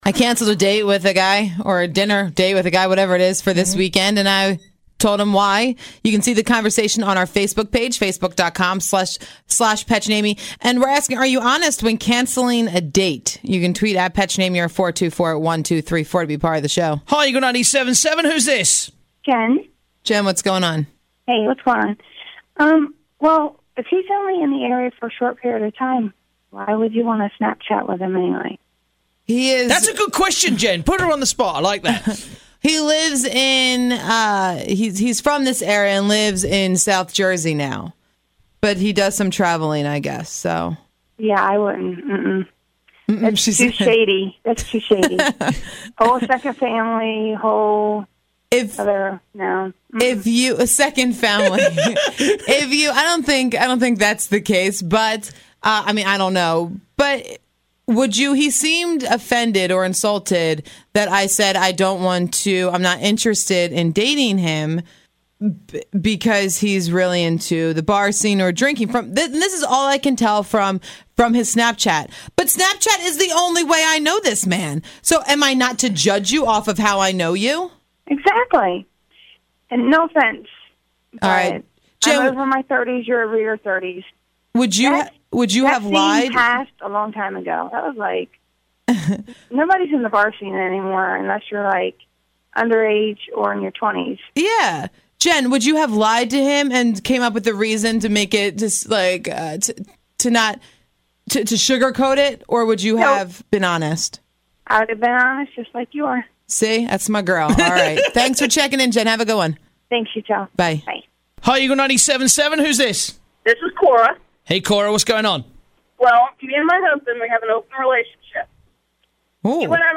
Being too Honest, Good or Bad - Your Calls